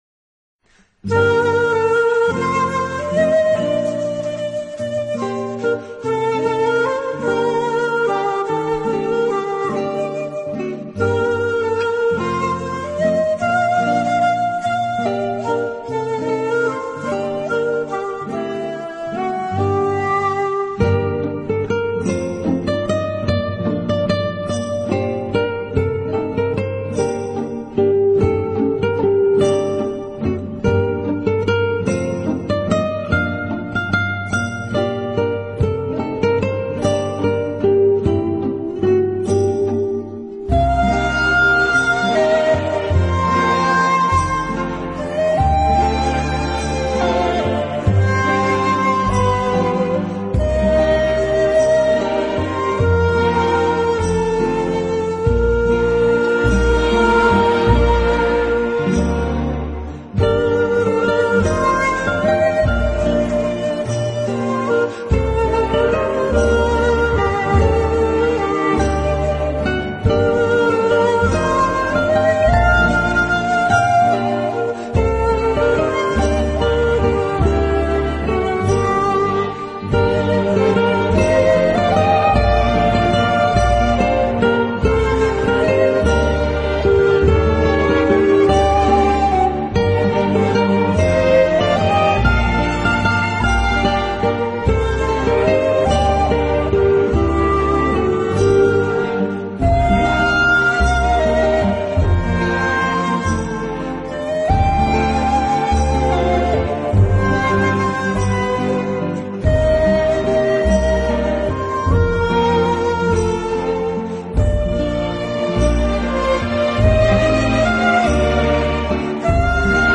音乐类型：Asia 亚洲音乐
音乐风格：New Age/Asia Folk